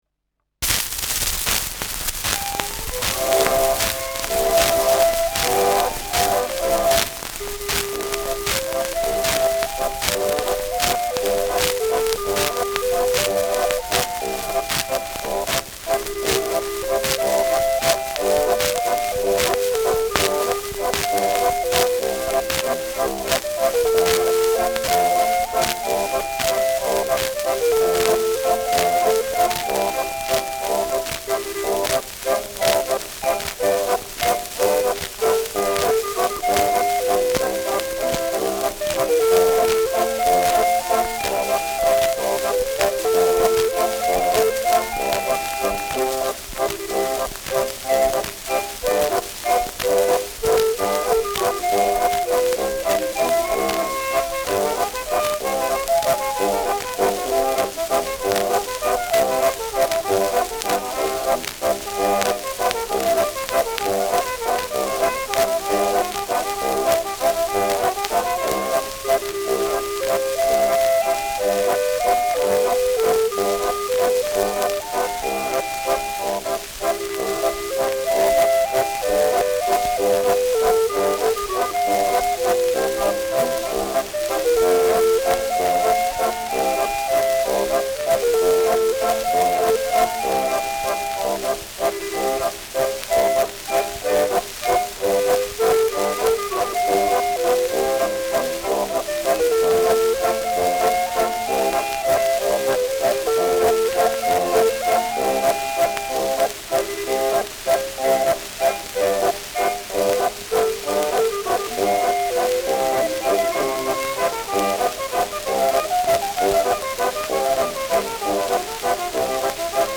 Schellackplatte
starkes Nadelgeräusch : abgespielt : leiert : präsentes Rauschen : gelegentliches Knacken
Dachauer Bauernkapelle (Interpretation)